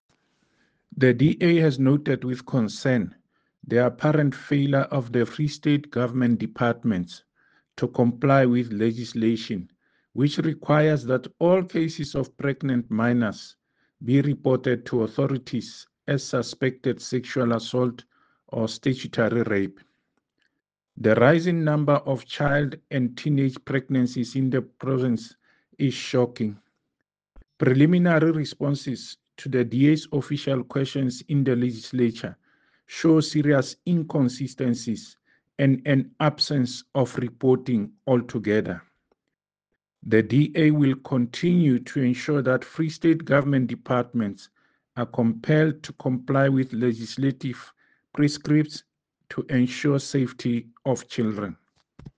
Sesotho soundbites by David Masoeu MPL and